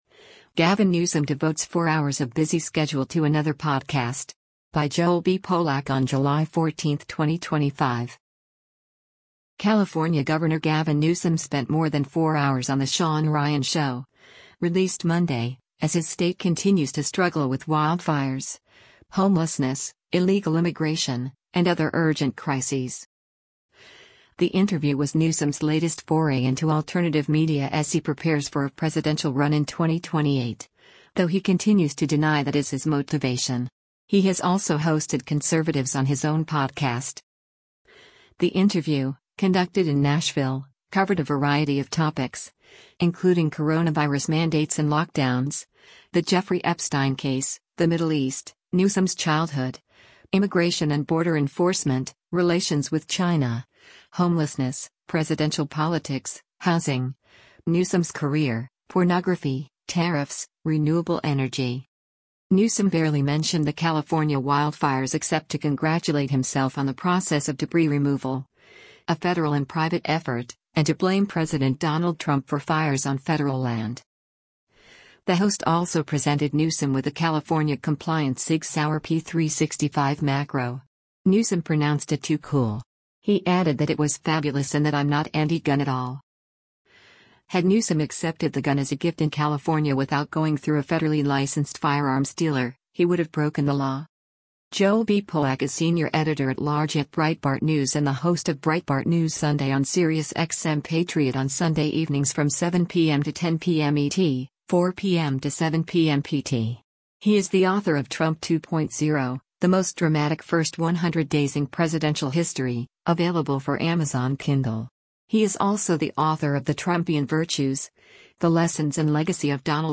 The interview, conducted in Nashville, covered a variety of topics, including coronavirus mandates and lockdowns; the Jeffrey Epstein case; the Middle East; Newsom’s childhood; Immigration and border enforcement; relations with China; homelessness; presidential politics; housing; Newsom’s career; pornography; tariffs; renewable energy.